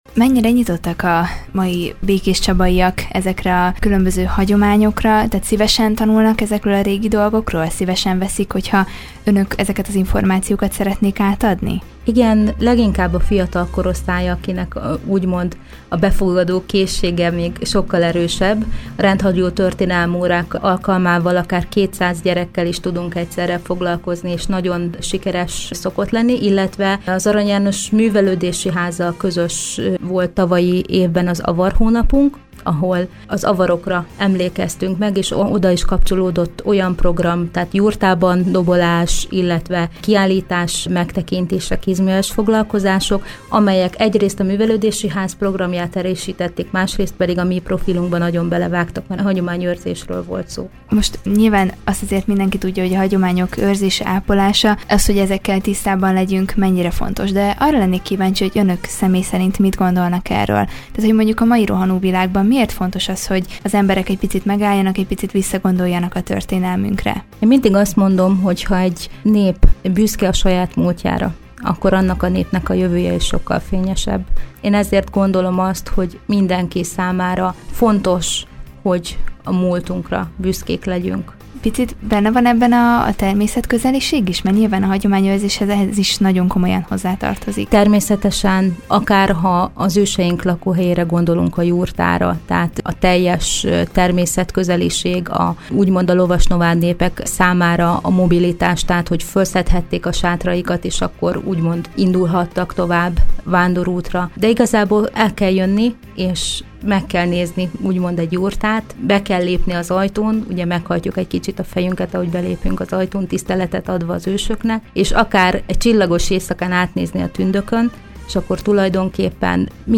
Velük beszélgetett tudósítónk az egyesület életéről valamint a hétvégén megrendezésre kerülő Csaba királyfi ünnepéről.